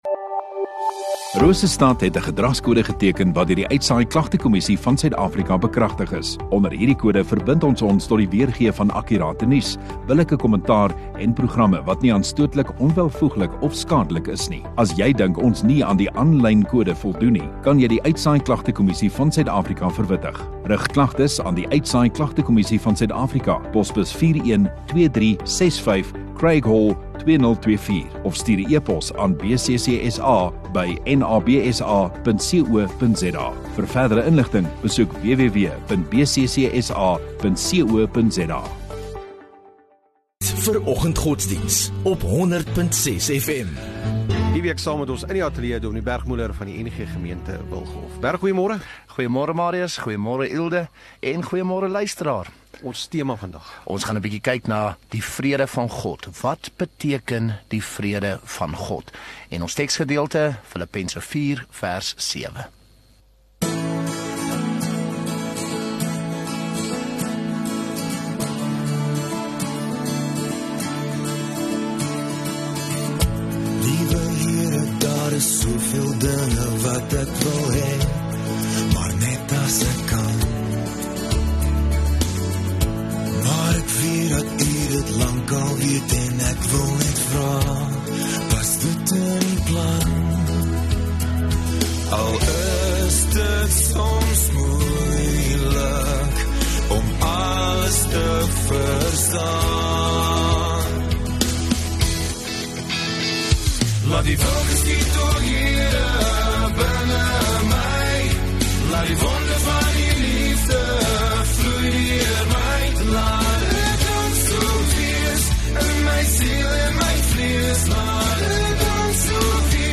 19 Sep Donderdag Oggenddiens